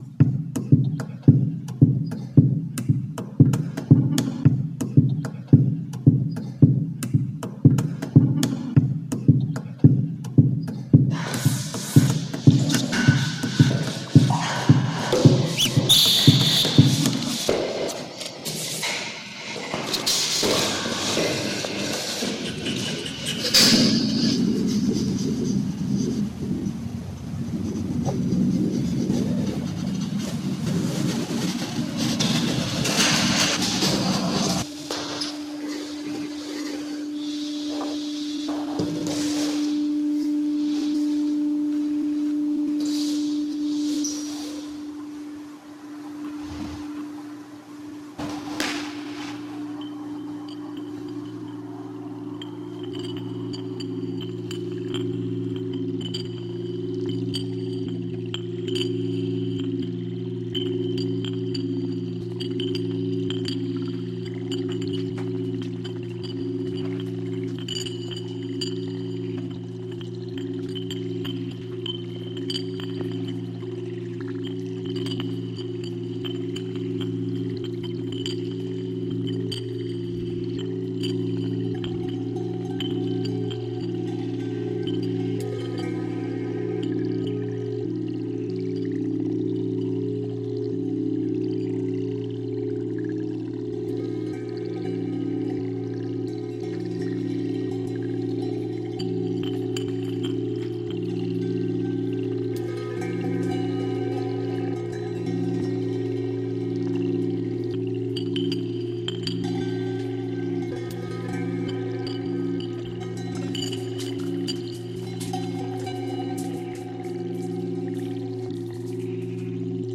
Collage Sonido iCoDaCo.mp3
Title en Collective contribution sound Description en Spanish collective contribution Tier 8 on sound (collage) ContentConcept en Tier8 en Sound en Collage File Date en 2025-09-19 Type en Audio Tier en 8.